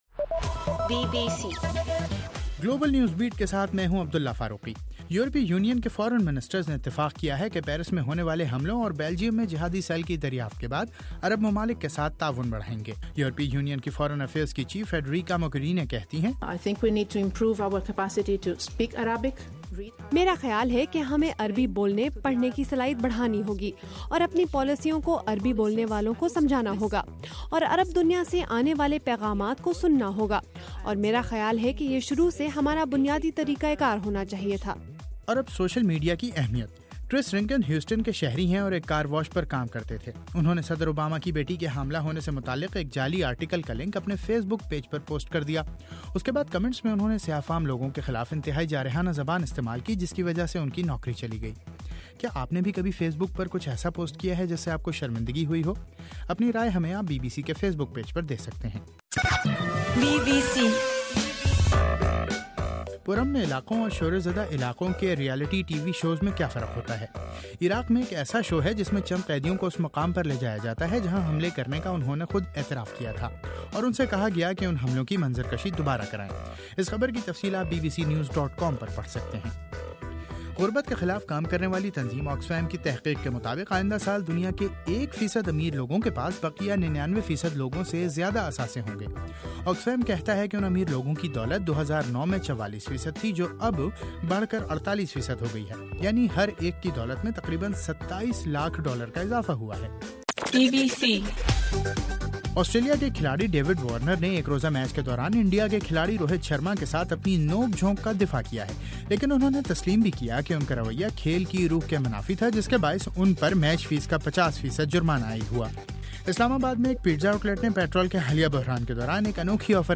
جنوری 20: صبح 1 بجے کا گلوبل نیوز بیٹ بُلیٹن